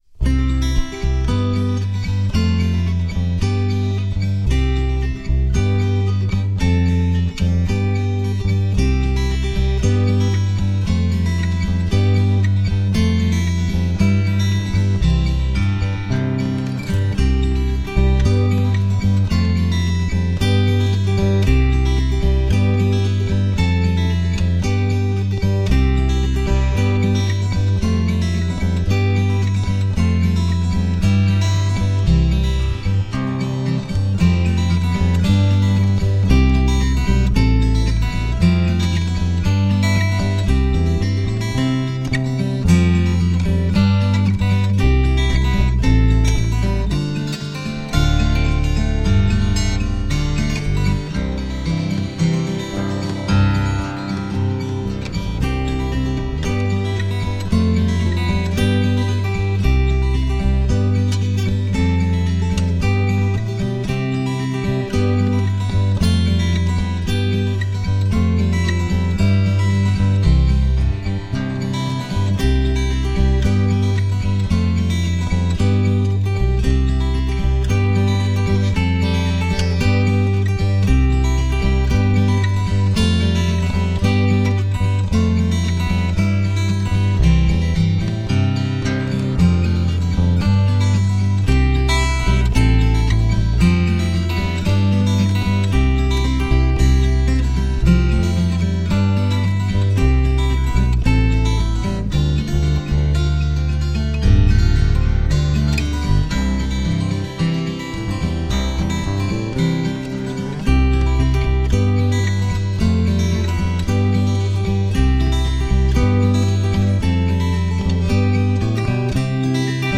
Reflective instrumental guitar melodies.